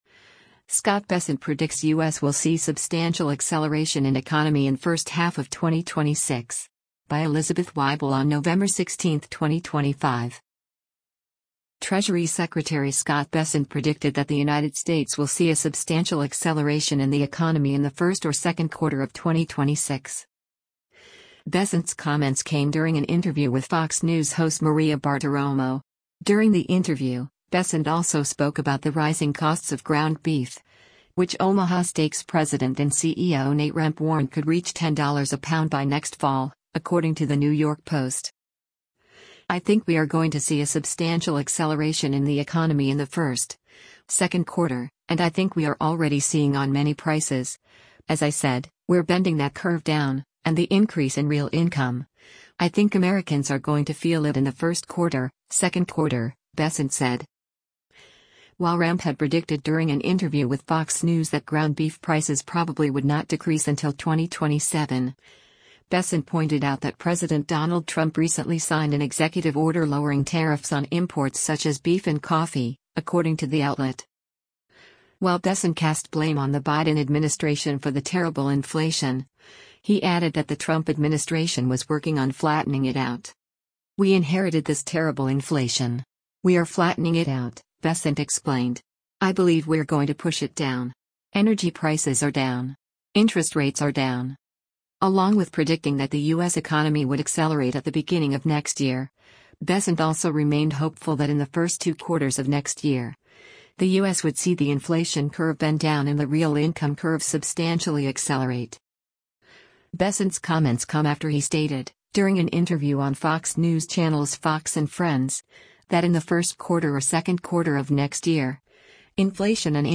Bessent’s comments came during an interview with Fox News host Maria Bartiromo.